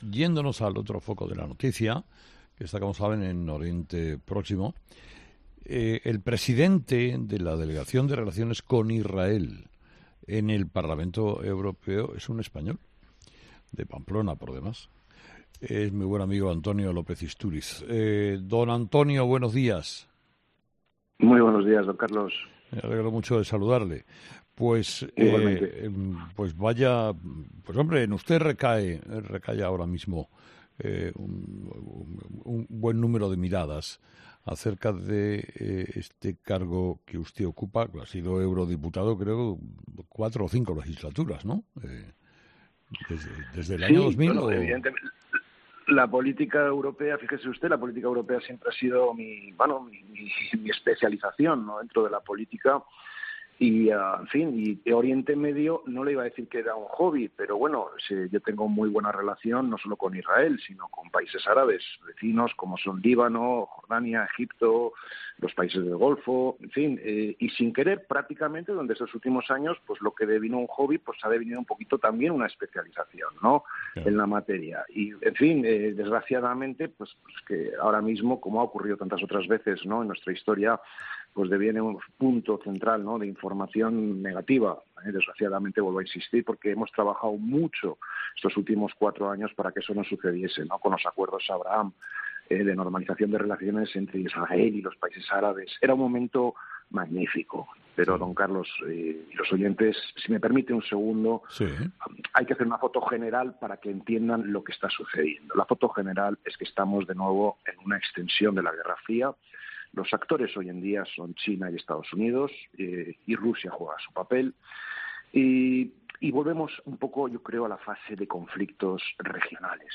Vuelve a escuchar la entrevista a Antonio López-Istúriz en 'Herrera en COPE'